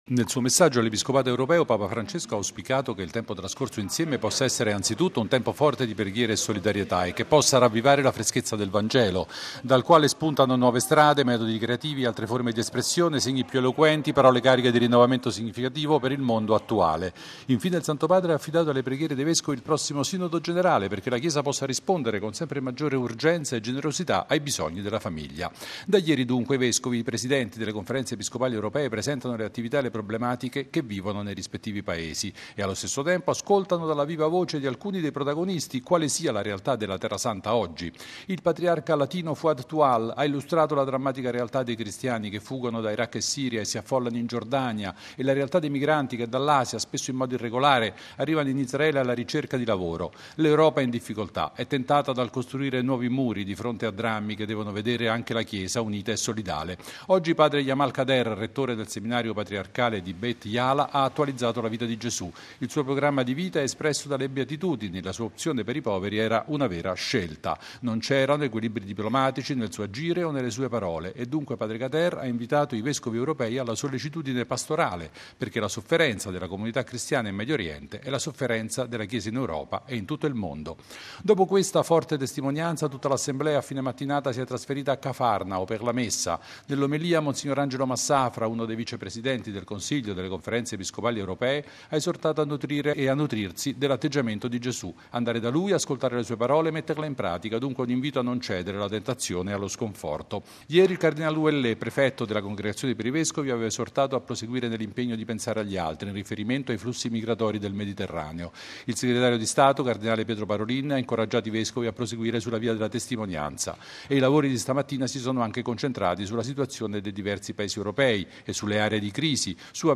I due temi procedono paralleli all’inizio dei lavori dell’Assemblea del Consiglio delle Conferenze episcopali europee, che si svolge oggi nella suggestiva cornice della Domus Galilaeae. Il servizio del nostro inviato da Cafarnao